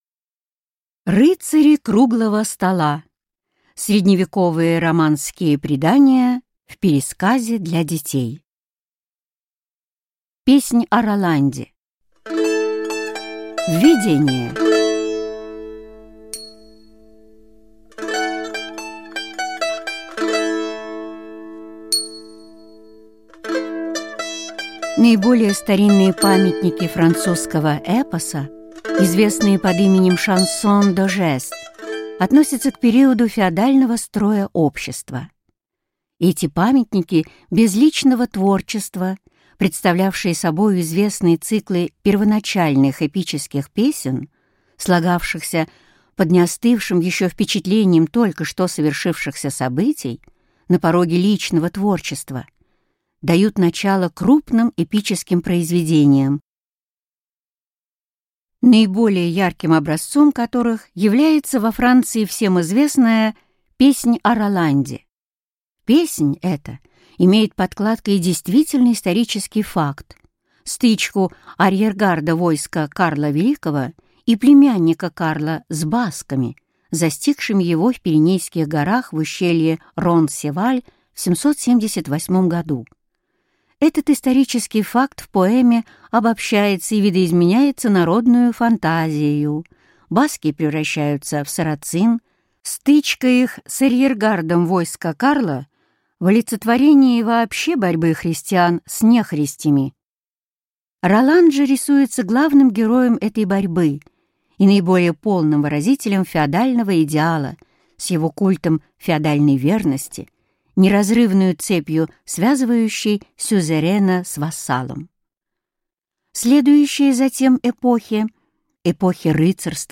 Аудиокнига Легенды и предания Средневековья о Рыцарях Круглого стола, Мерлине, поисках Грааля и о великом полководце Александре Македонском в пересказе для детей | Библиотека аудиокниг